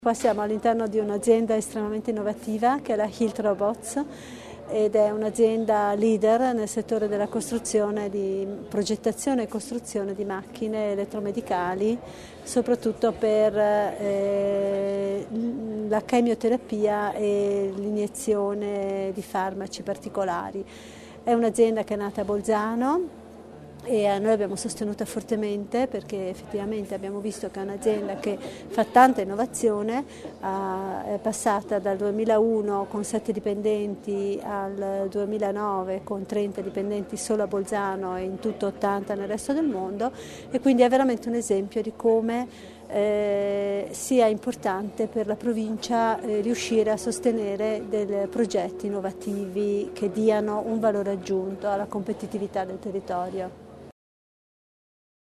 L'Assessore all'Innovazione Barbara Repetto sul nuovo robot